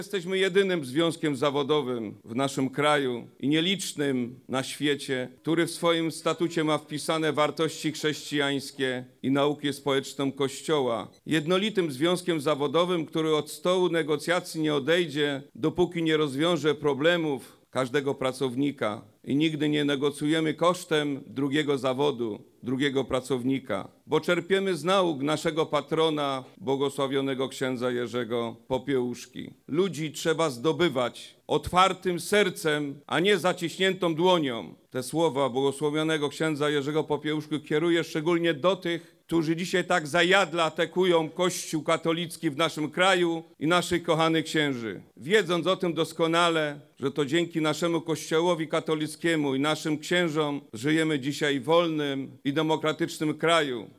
Podczas dzisiejszej uroczystości odsłonięcia pomnika błogosławionego księdza Jerzego Popiełuszki, głos zabrał Przewodniczący NSZZ „Solidarność” – Piotr Duda. Jako jedyny z przemawiających, w swoim wystąpieniu odniósł się do krytyki Kościoła katolickiego, która obecna jest w przestrzeni publicznej.